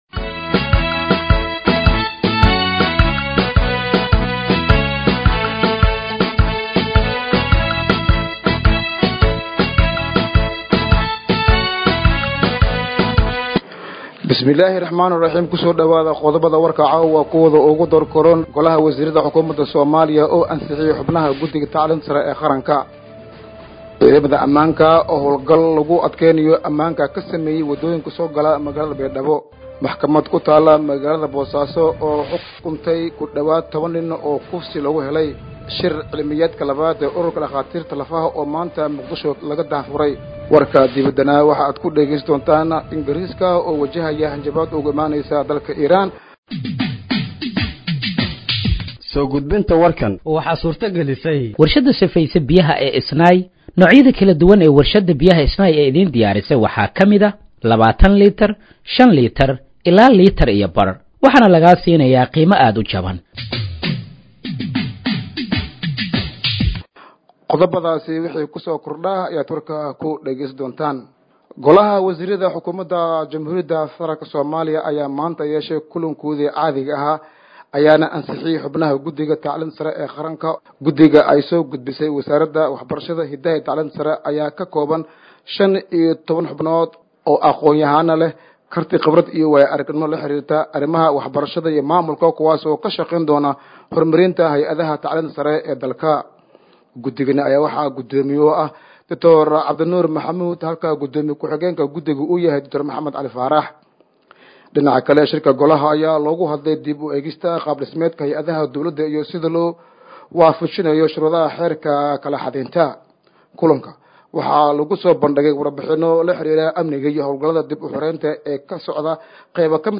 Dhageeyso Warka Habeenimo ee Radiojowhar 10/07/2025